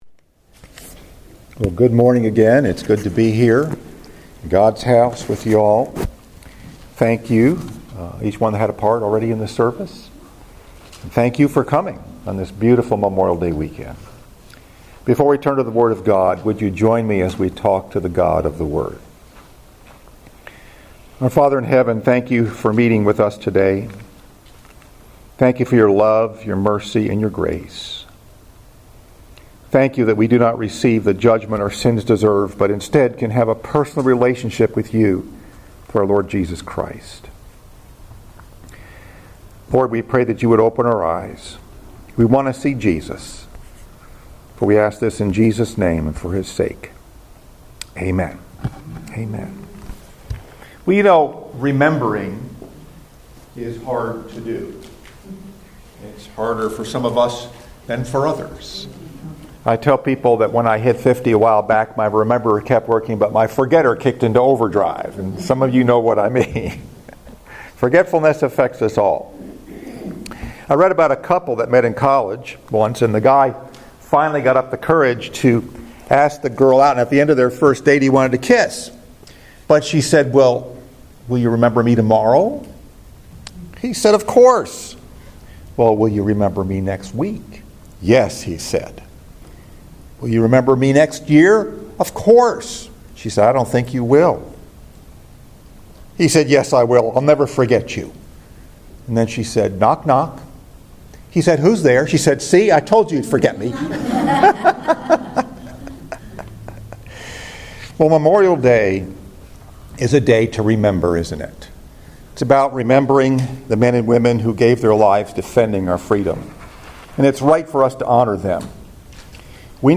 Message: “Remembering” Scripture: 2 Timothy 2:1-10